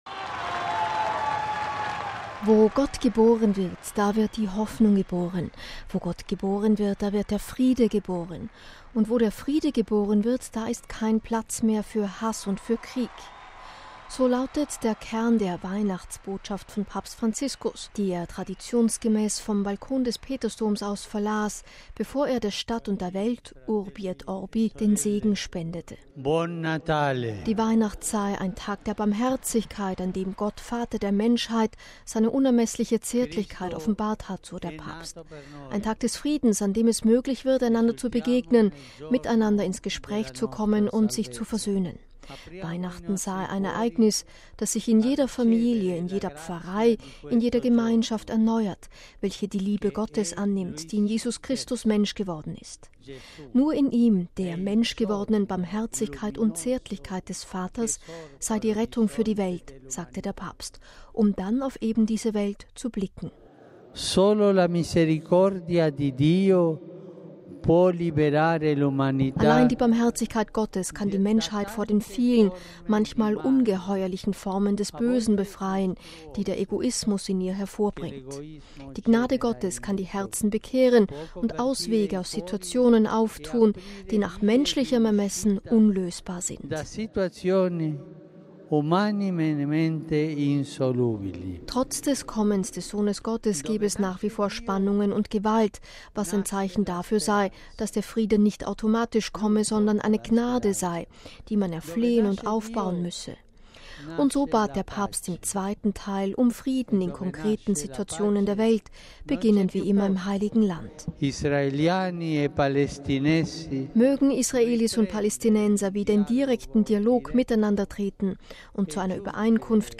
So lautete der Kern der Weihnachtsbotschaft von Papst Franziskus, die er traditionsgemäß vom Balkon des Petersdoms aus verlas, bevor er der Stadt und der Welt, Urbi et Orbi, den Segen spendete.